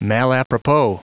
Prononciation du mot malapropos en anglais (fichier audio)
Prononciation du mot : malapropos